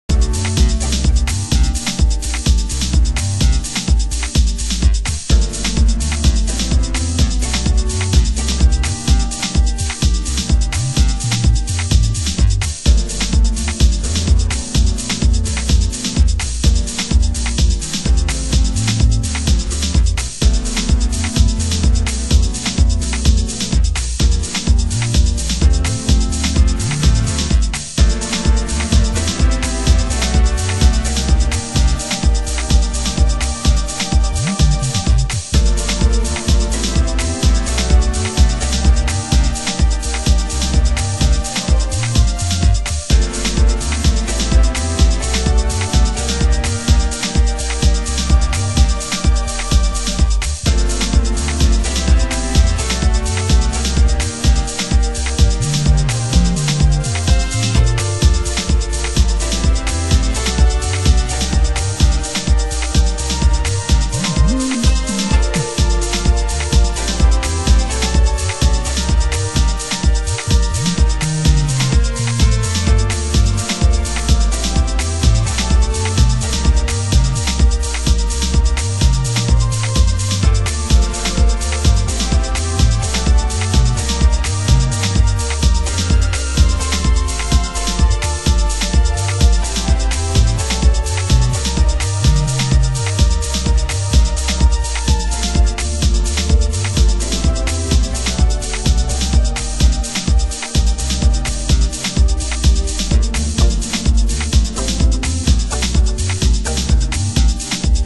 HOUSE MUSIC
Roots Instrumental